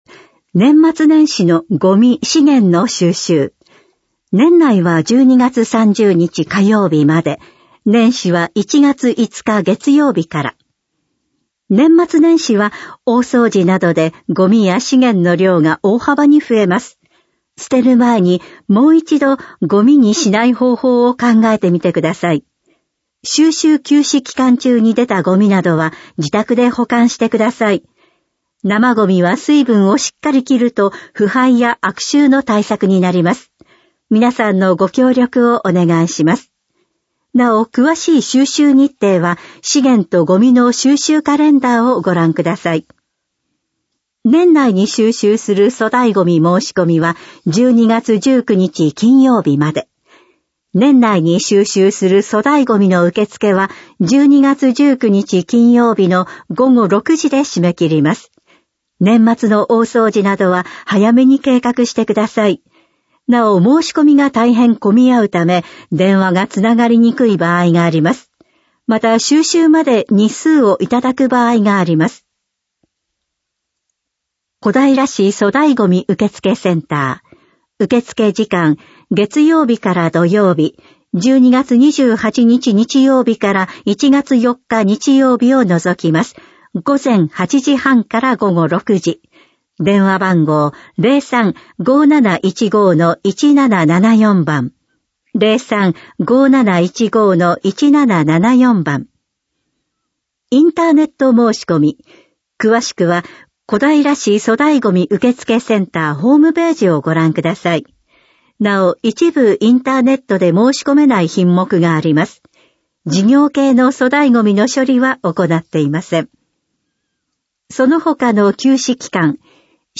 市報こだいら2025年12月5日号音声版